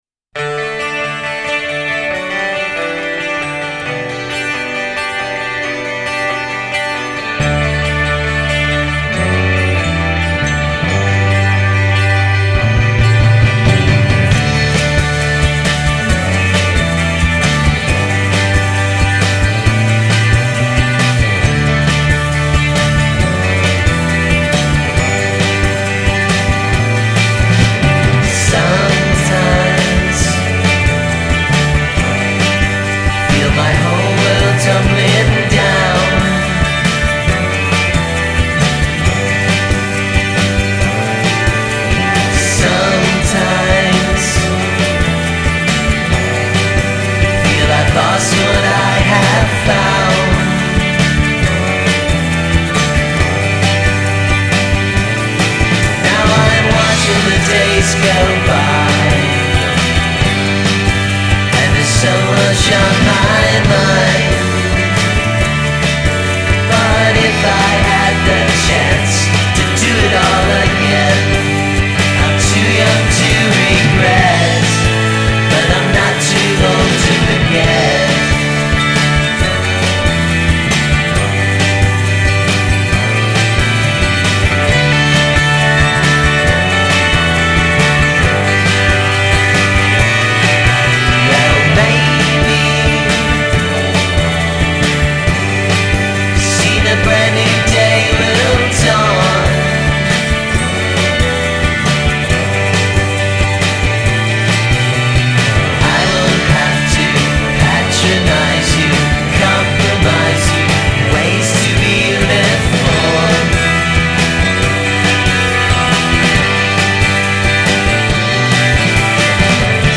quasi Teenage Fanclub